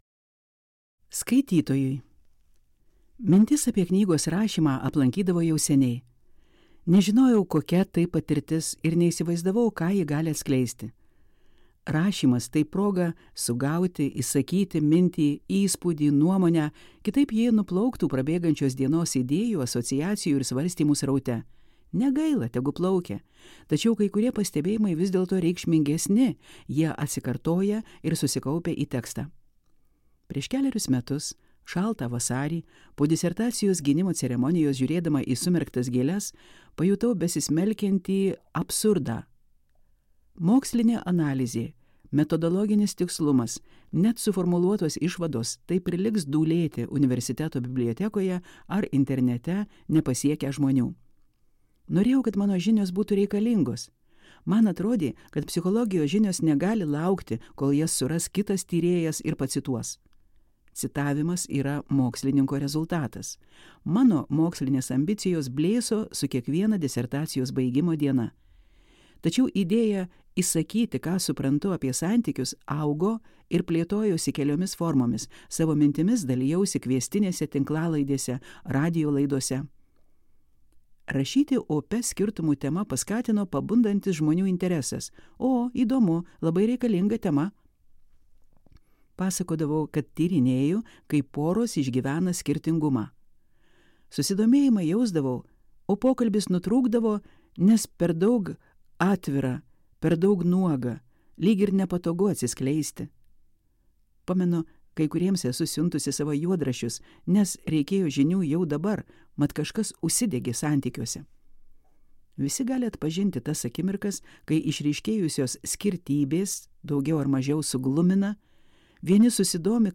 Audioknyga €10,85 Elektroninė knyga €8,29 €10,85 Įtraukti į norų sąrašą Siųsti draugui ar draugei Kiekis:: Į kr